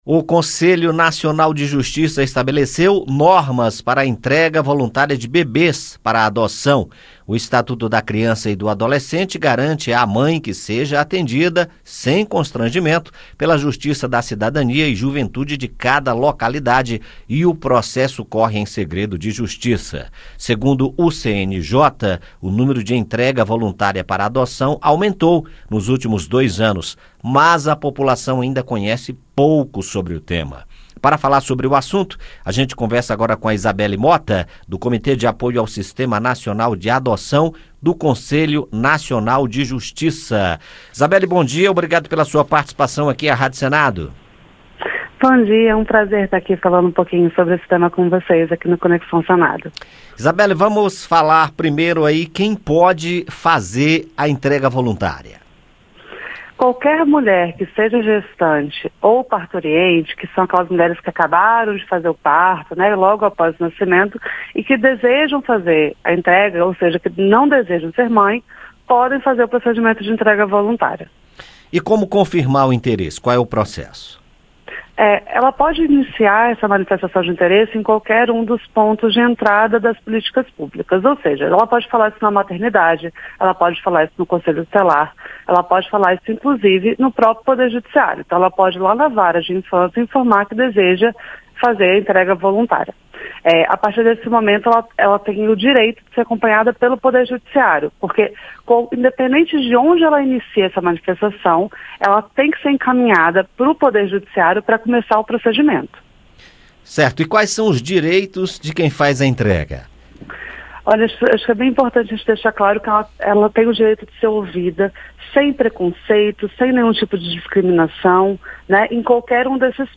Representante do CNJ explica como funciona a entrega voluntária de bebês para a adoção